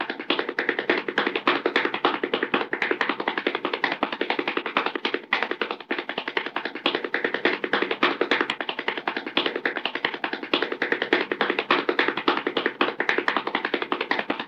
CARTOON - RUN 01
Category: Sound FX   Right: Both Personal and Commercial